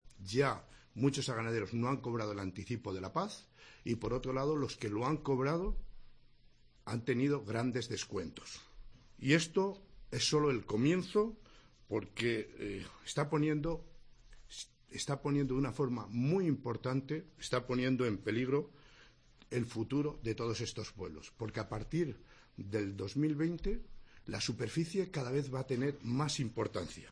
El portavoz socialista, Jesús Caro.